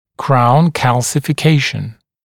[kraun ˌkælsɪfɪ’keɪʃn][краун ˌкэлсифи’кейшн]коронковая минерализация